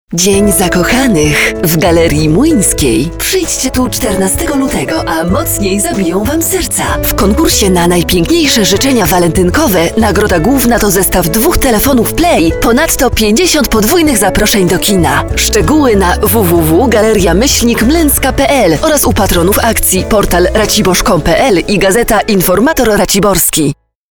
Professionelle polnische Sprecherin für TV / Rundfunk / Industrie.
Sprechprobe: Werbung (Muttersprache):